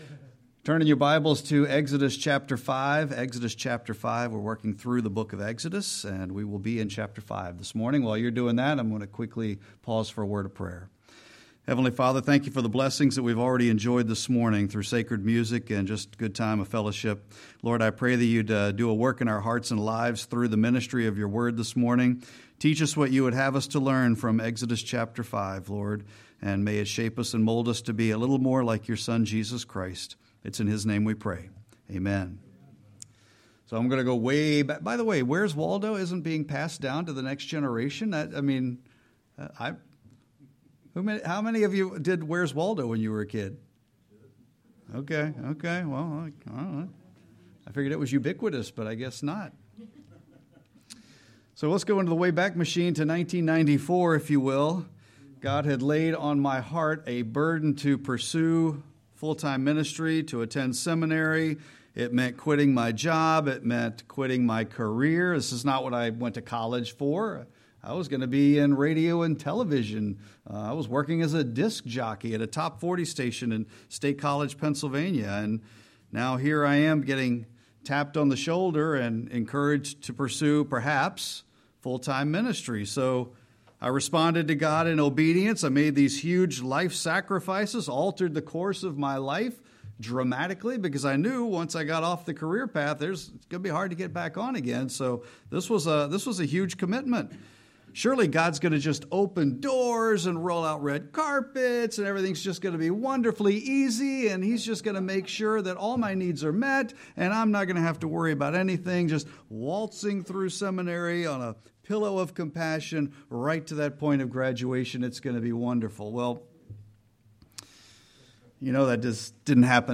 3-8-26-Sermon.mp3